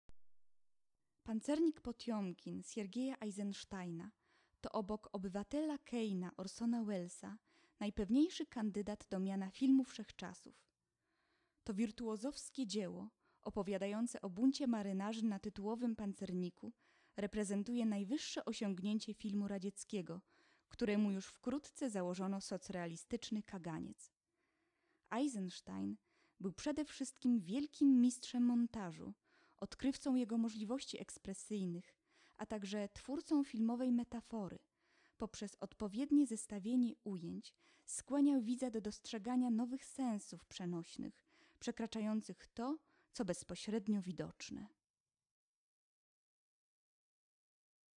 włącz  lektor